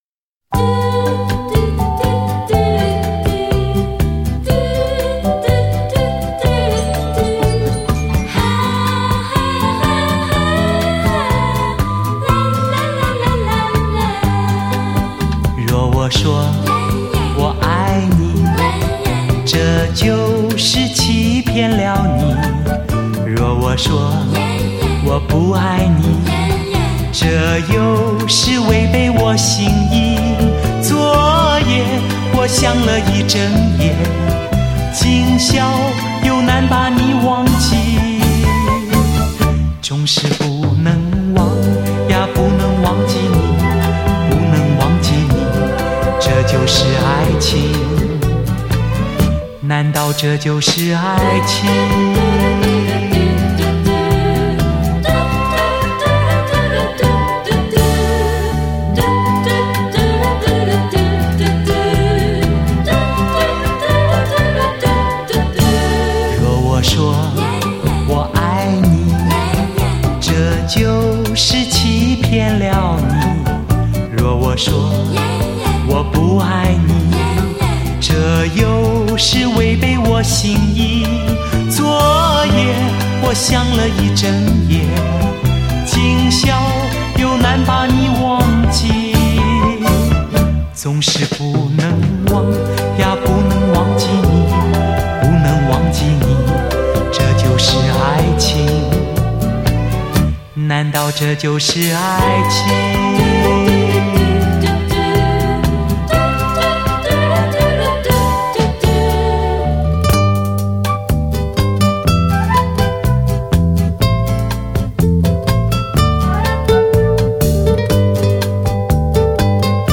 它运用了一套复杂的编码技术，大大减低了失真，更具临场感，使音乐“原汁原味”地体现出乐曲的神韵，是音乐发烧友的珍藏品。